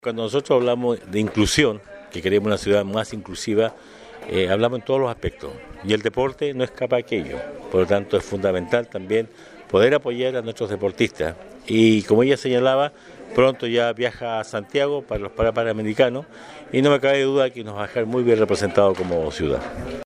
El alcalde Emeterio Carrillo indicó que estos jóvenes son un orgullo para la comuna, y merecen el apoyo y acompañamiento. Además, destacó el hecho que la ciudad cuenta con una deportista paralímpica que representará a Osorno en los para-panamericanos, y donde las políticas de inclusión del municipio, se extienden también a estos casos.